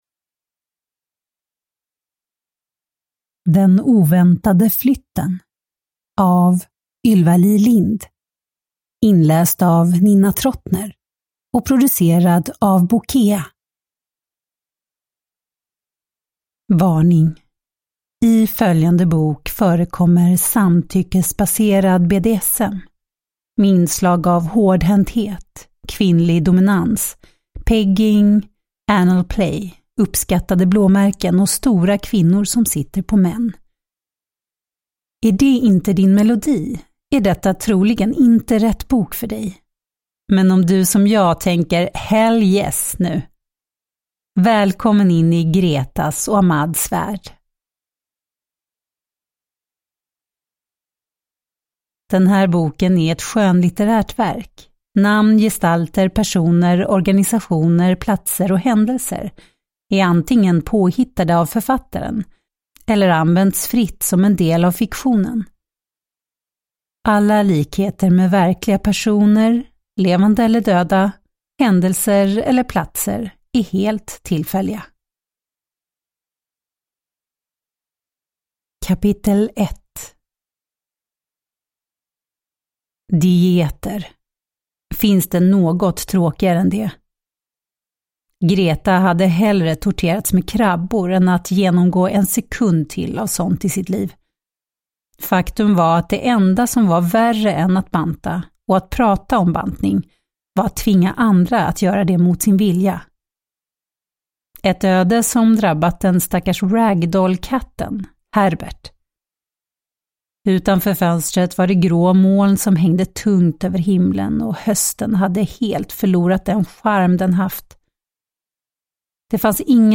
Den oväntade flytten (ljudbok) av Ylva-Li Lindh